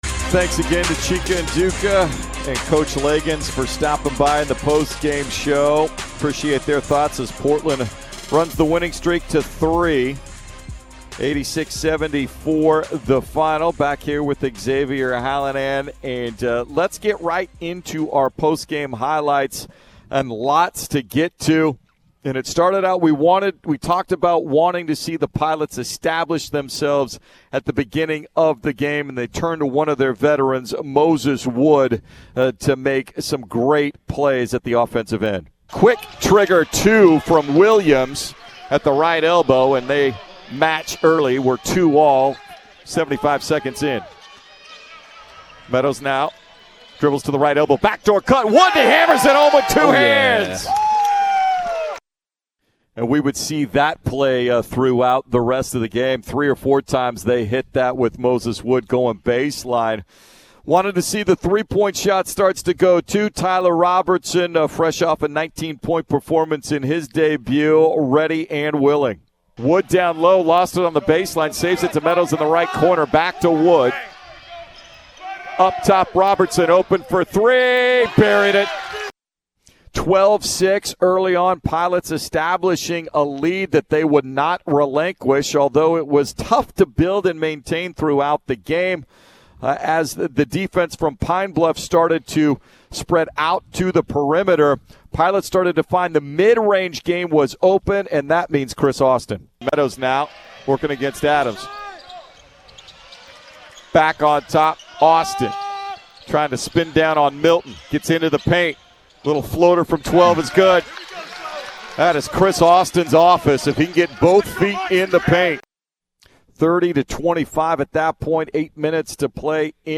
Radio Highlights vs. Arkansas-Pine Bluff